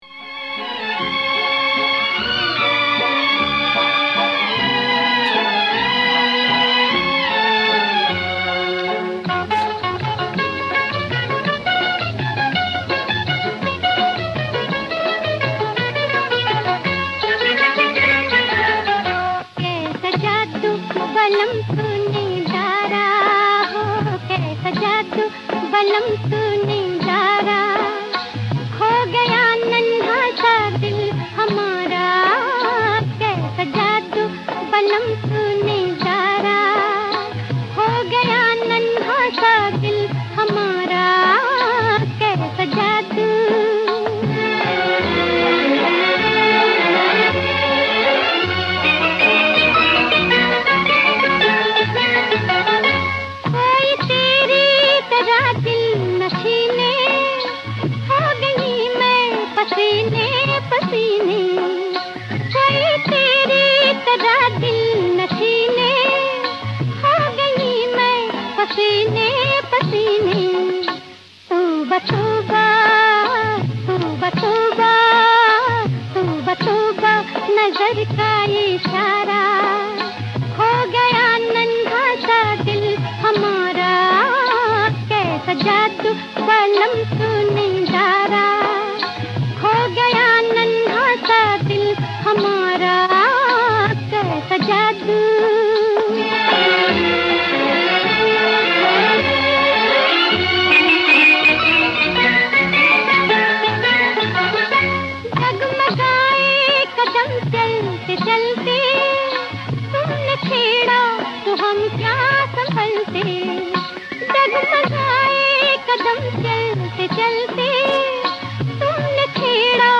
Raga Piloo